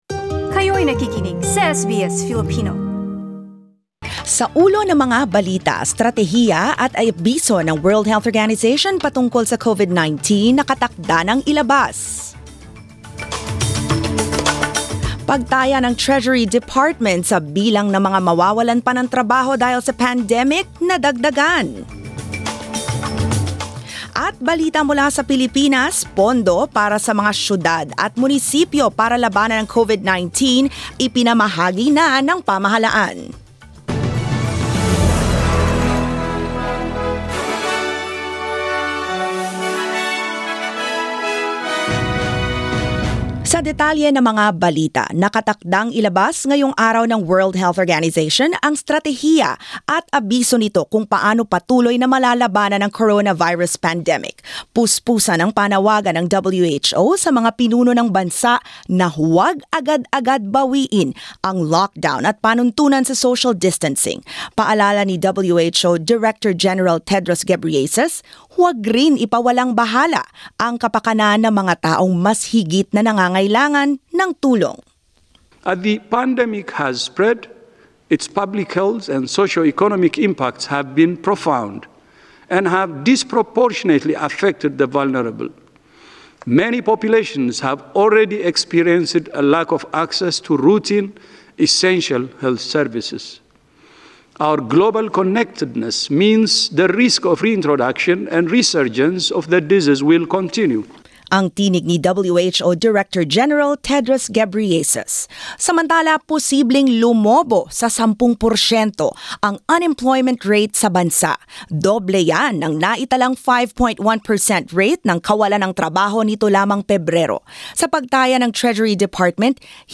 SBS News in Filipino, Tuesday 14 April